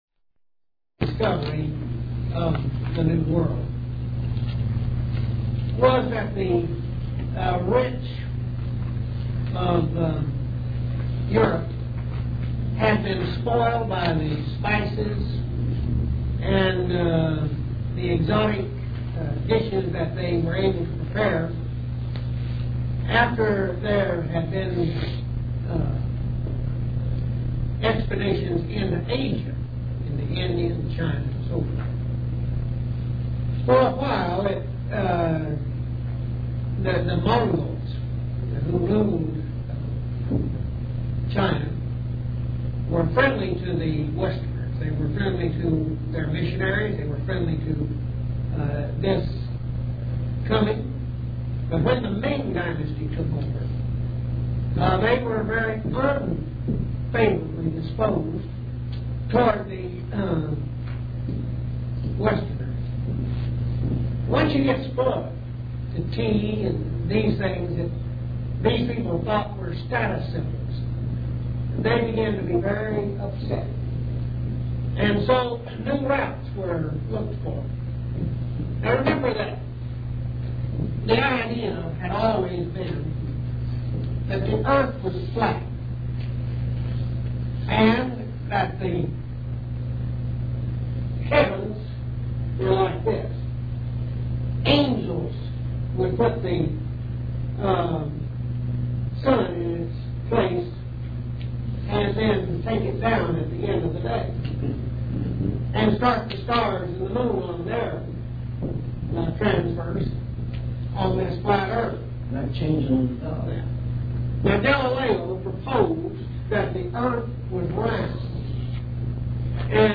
Each class was a classic.